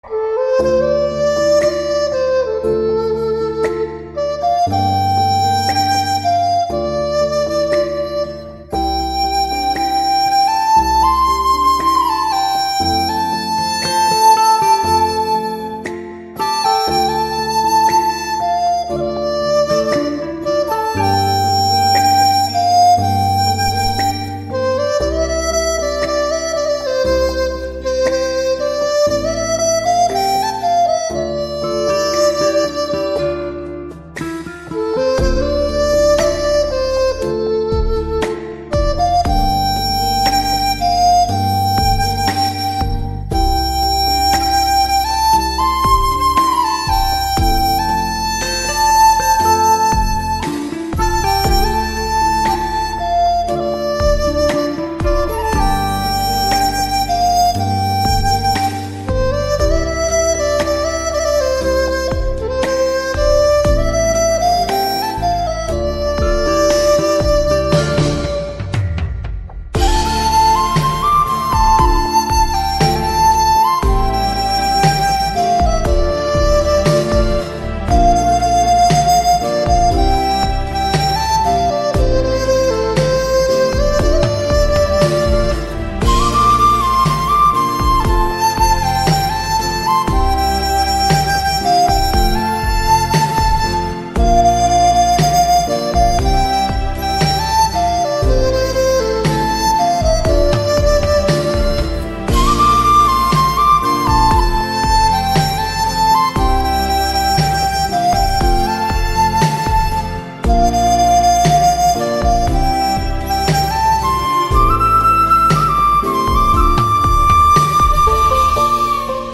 Giai điệu sáo trúc dân ca trữ tình, ngọt ngào và sâu lắng.